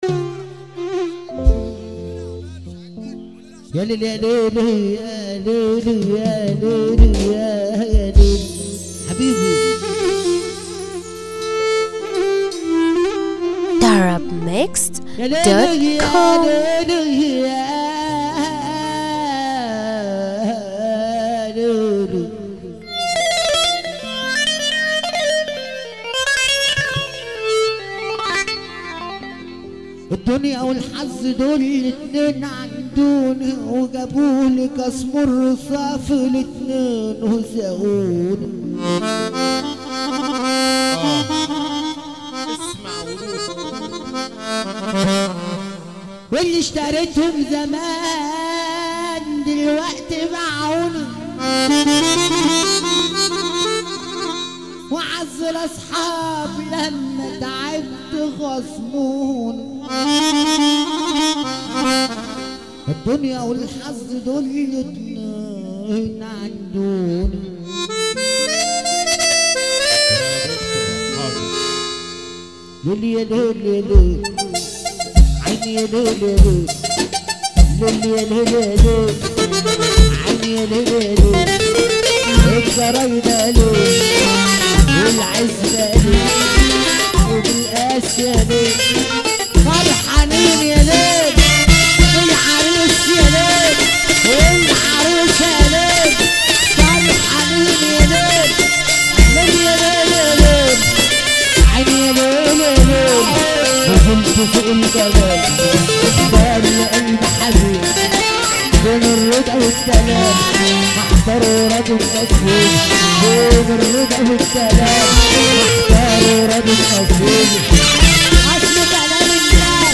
موال
بشكل حزين جدا